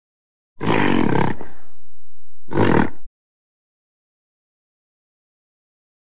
Gorilla Growl, Snort. Two Low Pitched Growls. Close Perspective.